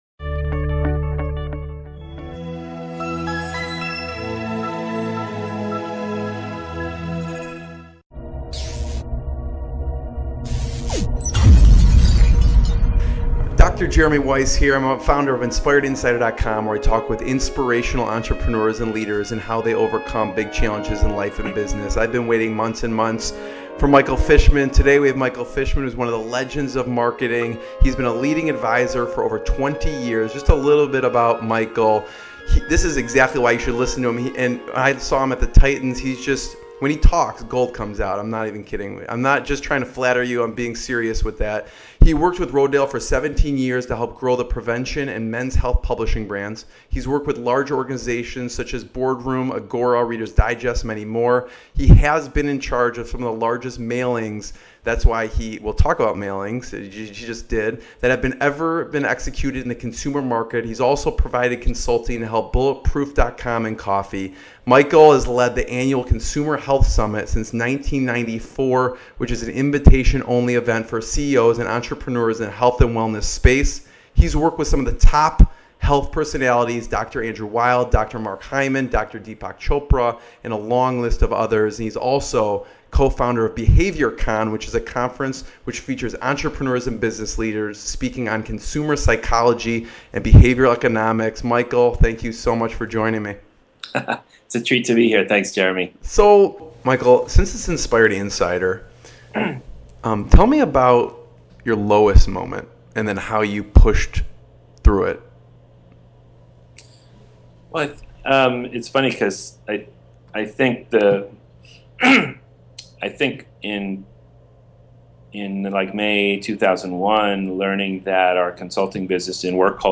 What will you learn in this interview?